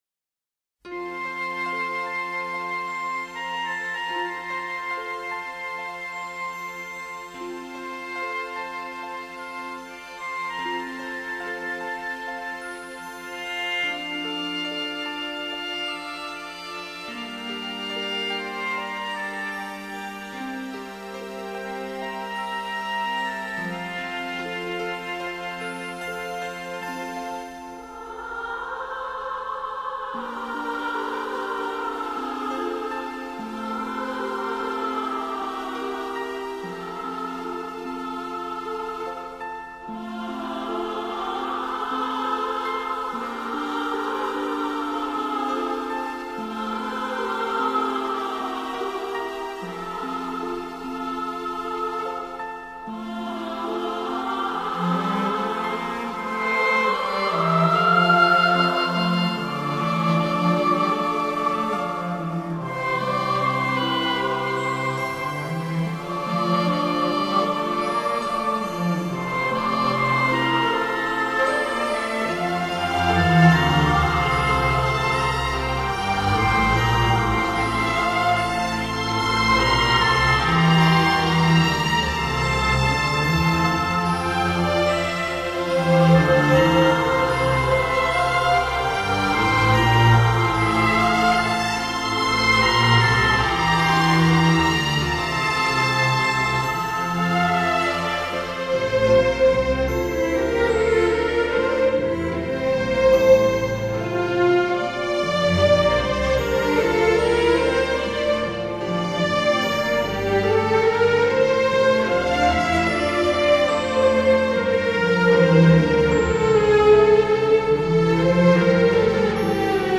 18/9/2010]纯音绕梁一百日之第6日<半个月亮爬上来> <半个月亮爬上来>原是中国著名的一首女声无伴奏合唱曲，是由作曲家王洛宾根据西北地区民间音调创作的。本音乐借用了女声和音，曲调优美，意境深远。